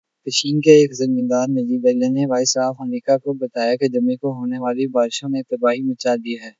deepfake_detection_dataset_urdu / Spoofed_Tacotron /Speaker_02 /262.wav